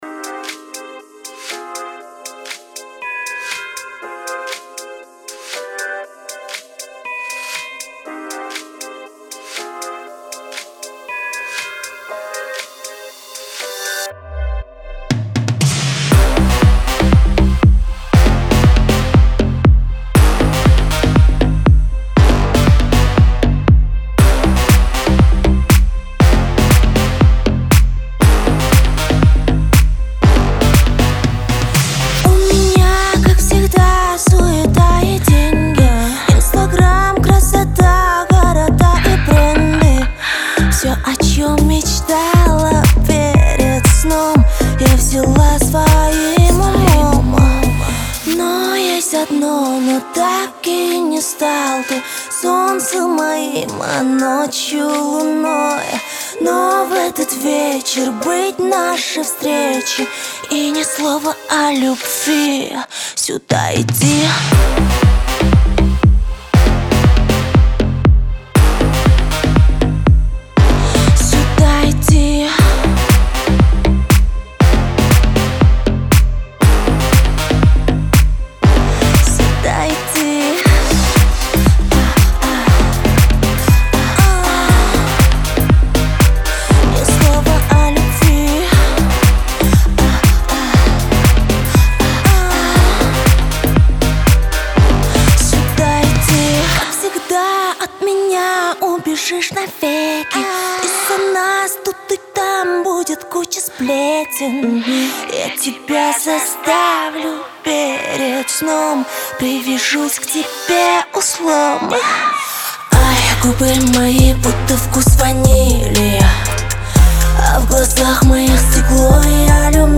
это яркий образец казахской поп-музыки